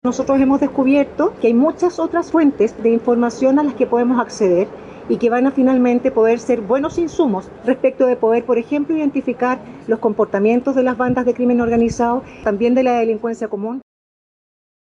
Al ser consultada al respecto, la subsecretaría de Prevención del Delito, Ana Victoria Quintana, dijo que ya se encuentra trabajando con Carabineros de Chile y Policía de Investigaciones para establecer coordinaciones que eviten estos hechos. Asimismo, hizo hincapié en la generación de estadística y estudios que permitan conocer el comportamiento de quienes cometen estos delitos.